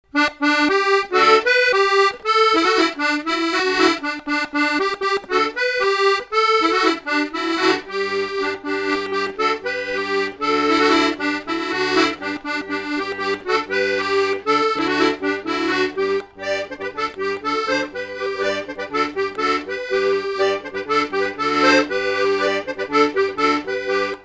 Résumé instrumental
danse : brisquet
Pièce musicale inédite